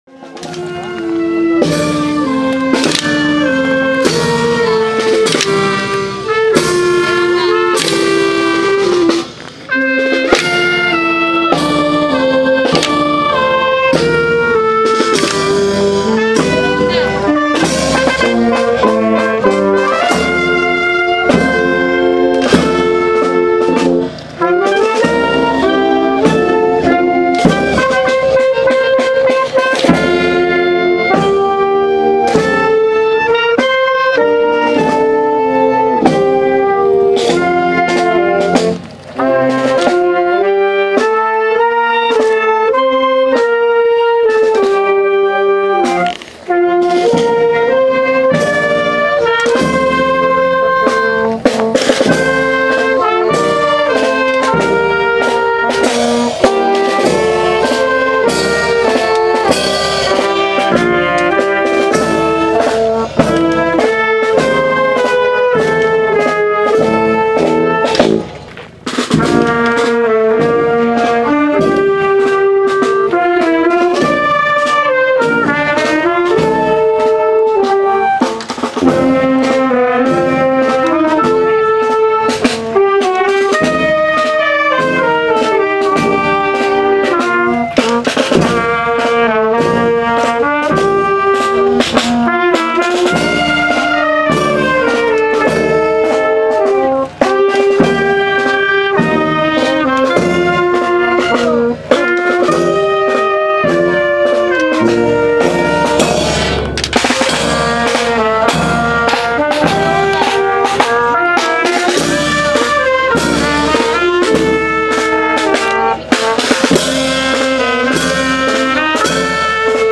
Procesión Viñas-Carivete 2014